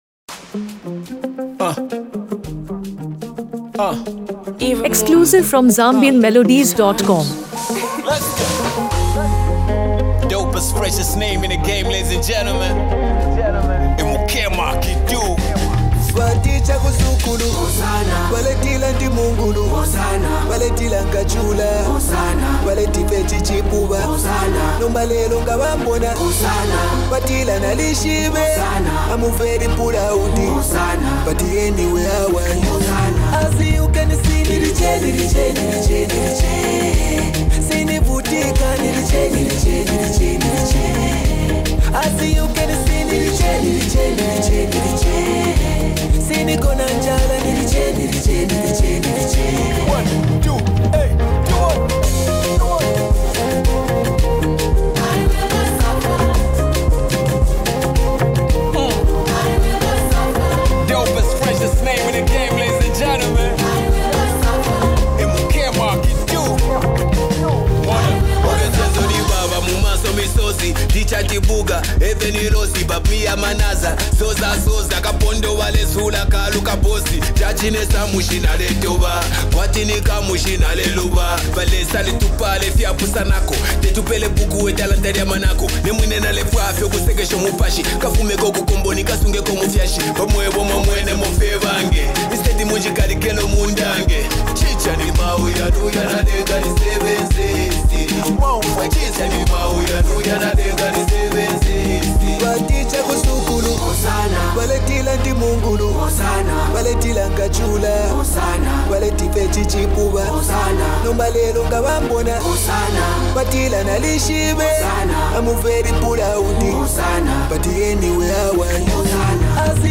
an inspiring and emotionally rich song